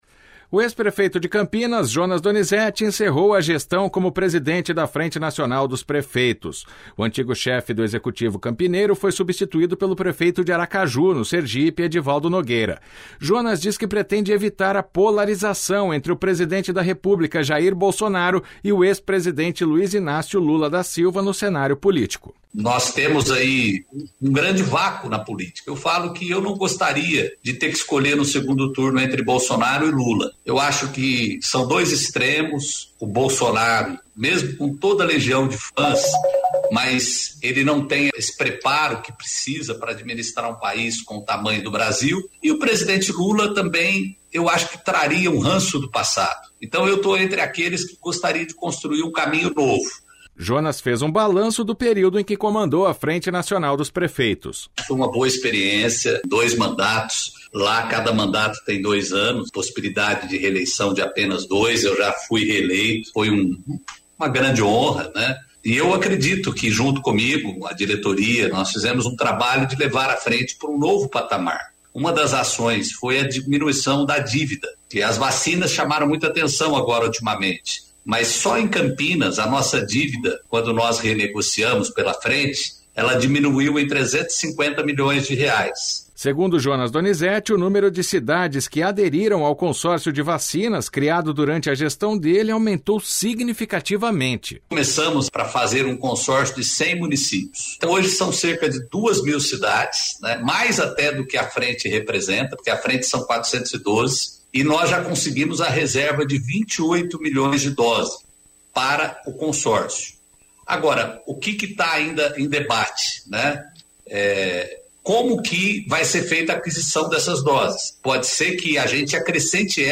Em entrevista à CBN Campinas, o ex-prefeito fez um balanço do período em que comandou a FNP.